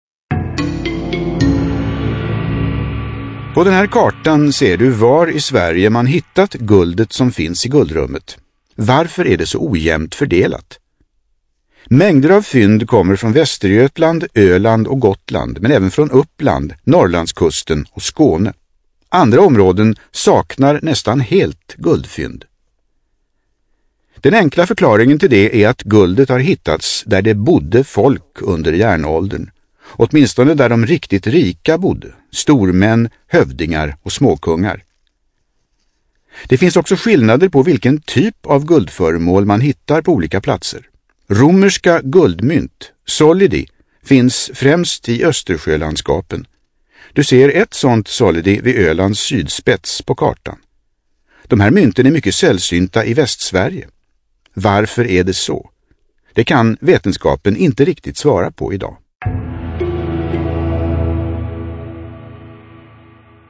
Inbjudan till Livrustkammarens skrivarstuga på Internationella kvinnodagen 2017. Bilagd finns även en ljudfil för en audioguide.